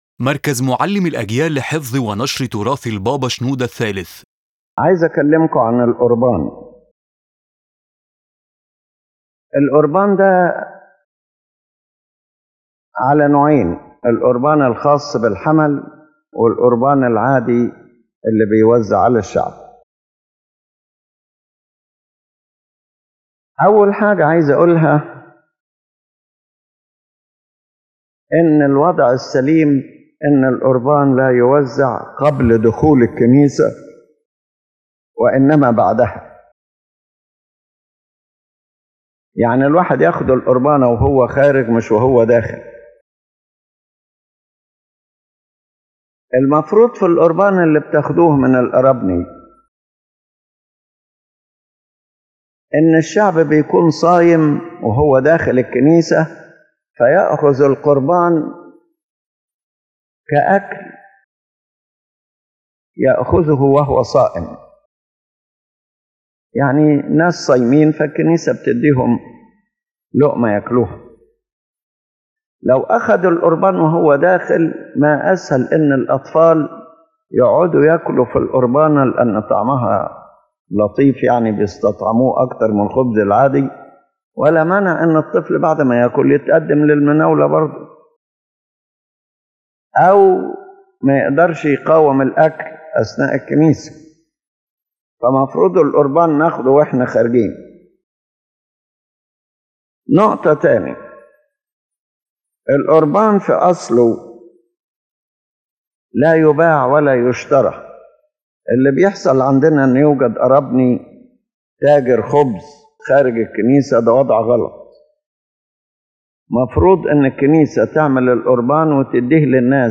The lecture presents a comprehensive theological and liturgical explanation of the Eucharistic Bread (Qorban) in the Coptic Orthodox Church, addressing its meaning, preparation, spiritual symbolism, and its central role in the Sacrament of the Eucharist.